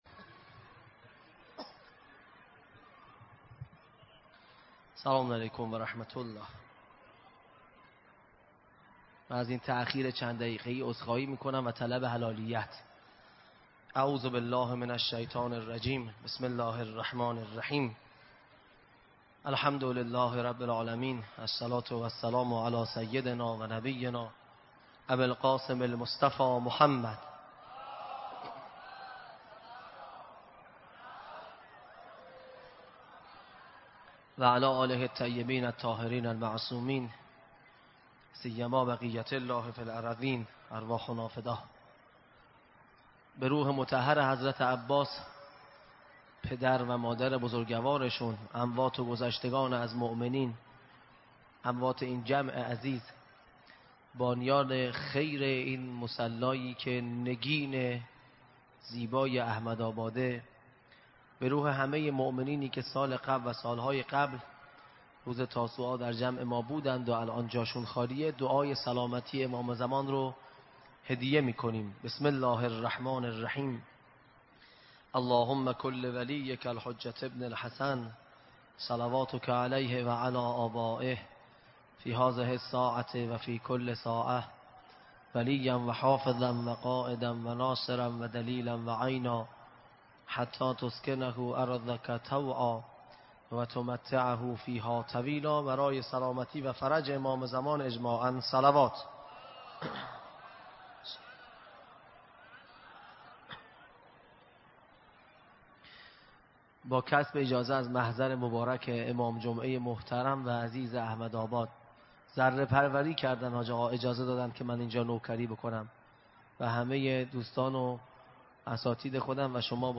مصلی خاتم الانبیاء احمدآباد